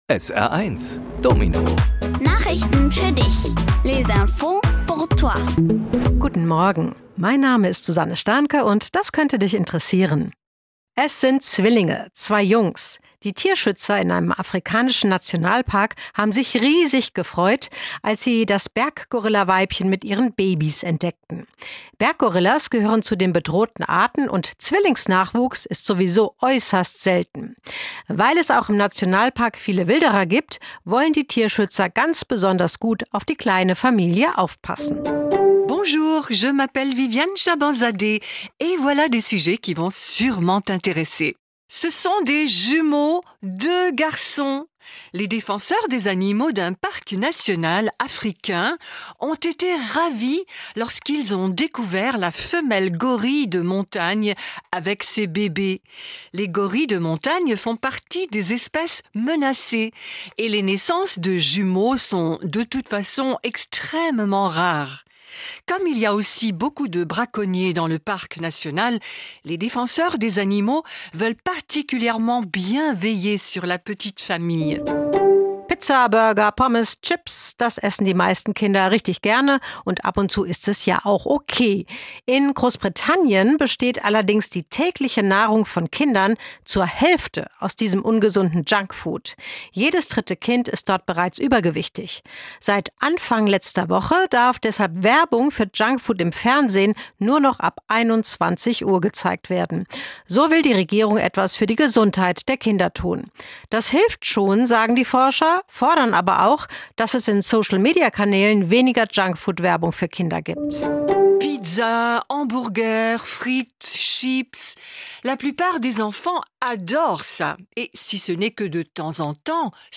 Kindgerechte Nachrichten auf Deutsch und Französisch: